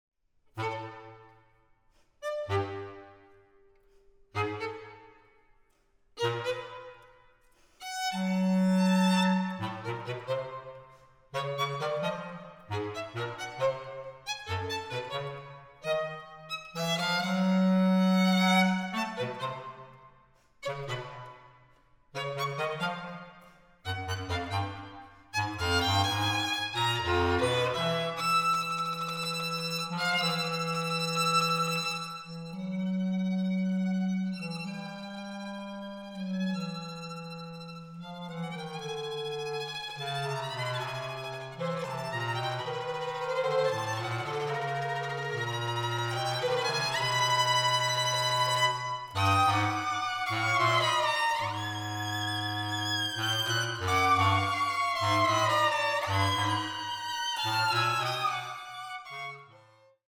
Recording: Festeburgkirche Frankfurt, 2021
für Holzbläserquintett